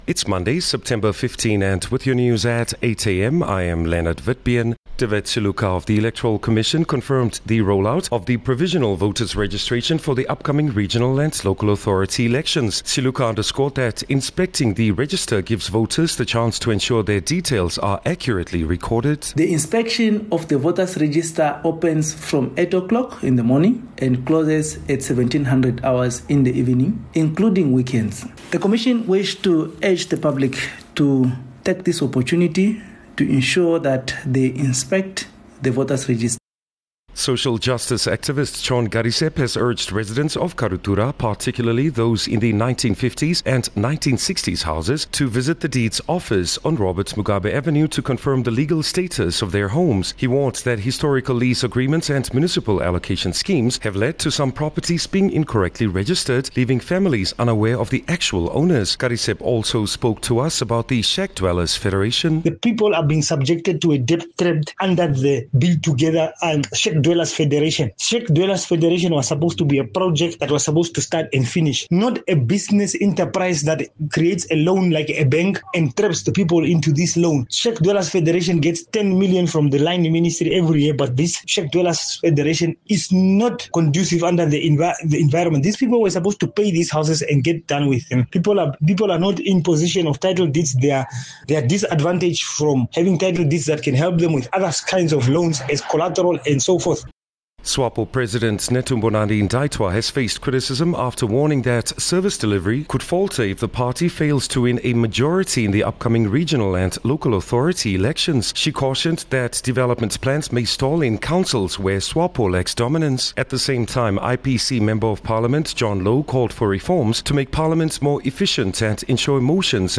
15 Sep 15 September-8am news